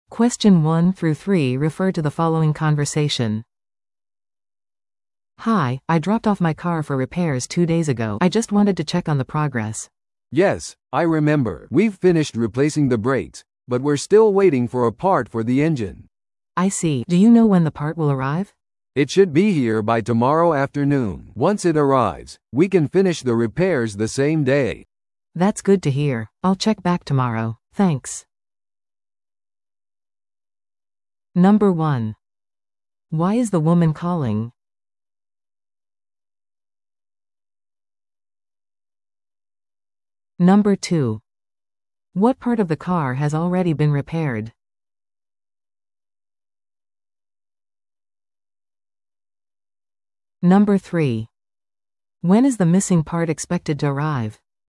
No.1. Why is the woman calling?